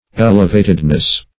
Elevatedness \El"e*va`ted*ness\, n.
elevatedness.mp3